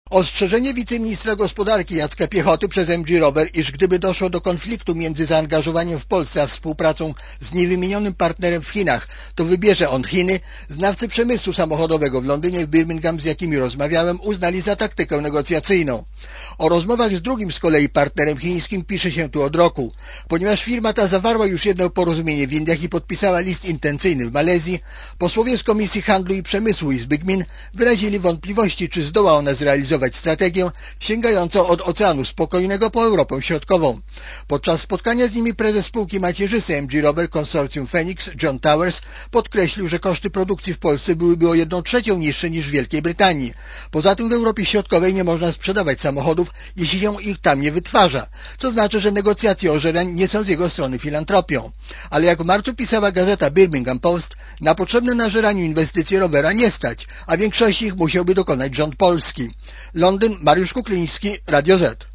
Korespondencja z Londynu